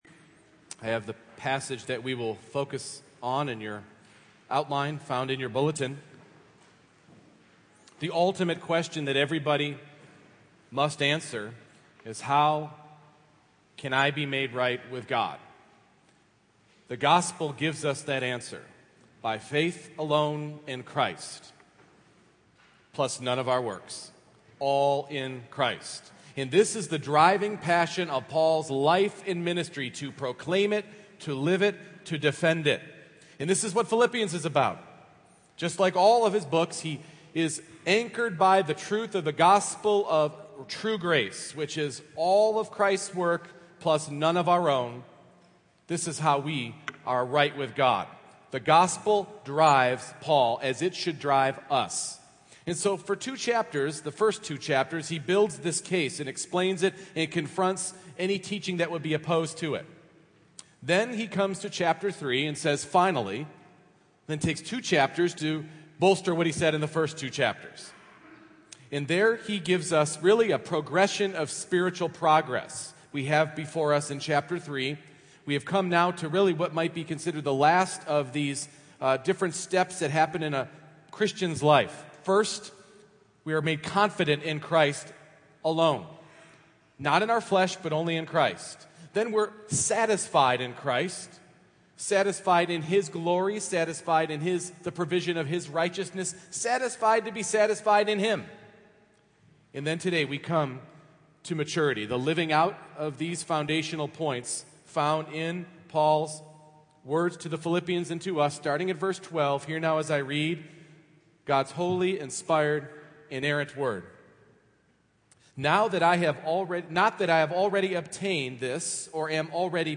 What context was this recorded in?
Philippians 3:12-16 Service Type: Morning Worship All of us probably agree with these statements